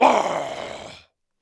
dead_1_1.wav